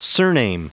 Prononciation du mot surname en anglais (fichier audio)
Prononciation du mot : surname